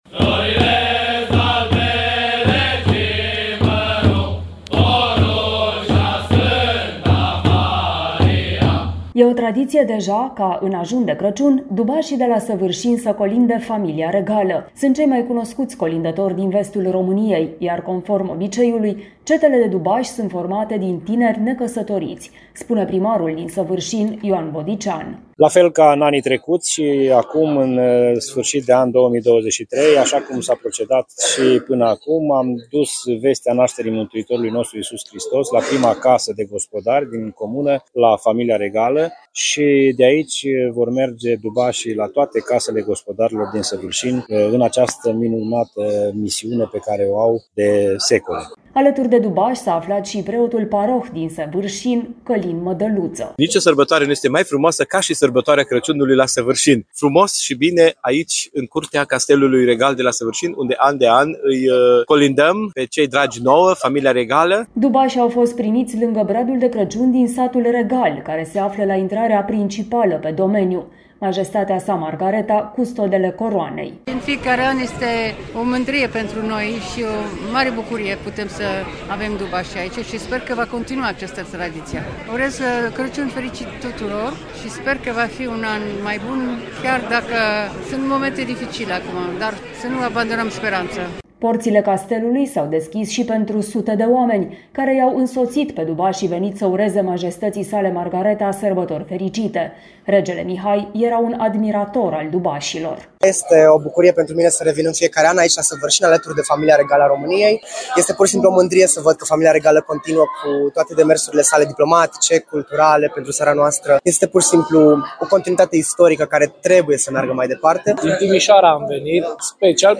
Dubașii au fost primiți lângă bradul de Crăciun din Satul Regal, care se află la intrarea principală pe domeniu.
Majestatea Sa Margareta, Custodele Coroanei, i-a primit pe dubași și și-a exprimat speranța că această tradiție va dăuinui.
Porțile castelului s-au deschis și pentru sute de oameni care i-au însoțit pe dubașii venit să ureze “Sărbători fericite” Majestății Sale Margareta.